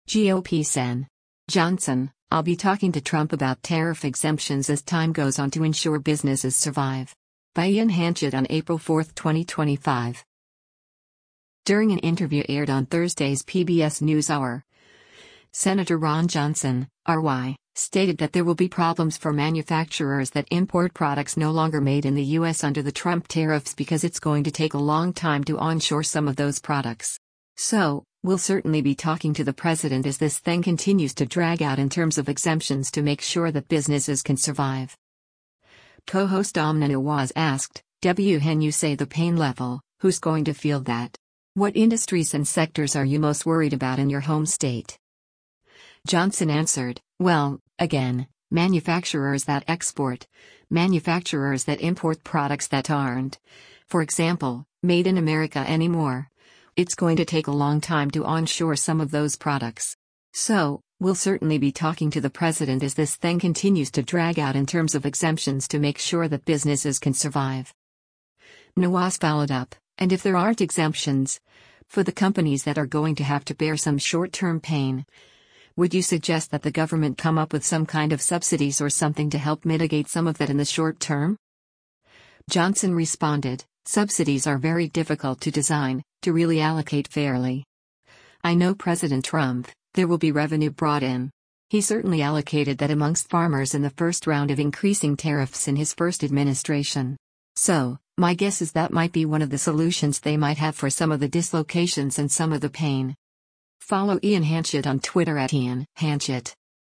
During an interview aired on Thursday’s “PBS NewsHour,” Sen. Ron Johnson (R-WI) stated that there will be problems for manufacturers that import products no longer made in the U.S. under the Trump tariffs because “it’s going to take a long time to onshore some of those products. So, we’ll certainly be talking to the president as this thing continues to drag out in terms of exemptions to make sure that businesses can survive.”
Co-host Amna Nawaz asked, “[W]hen you say the pain level, who’s going to feel that? What industries and sectors are you most worried about in your home state?”